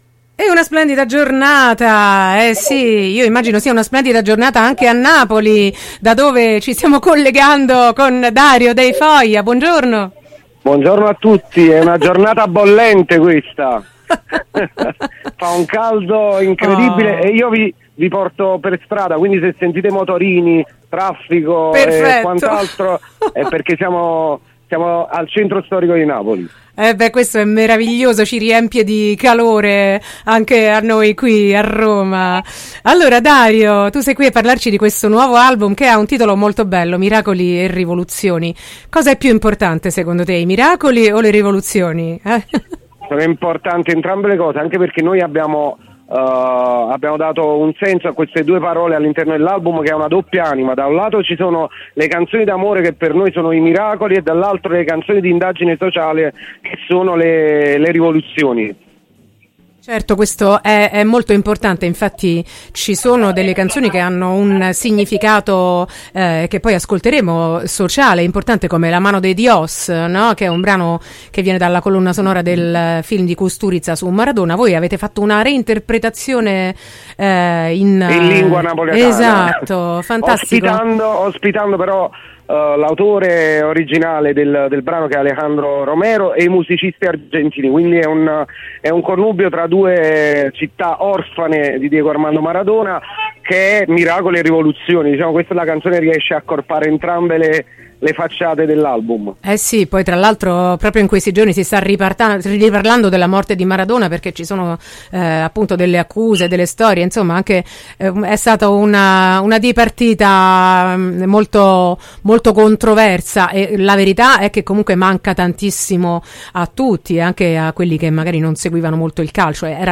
intervista-foja-15-4-22.mp3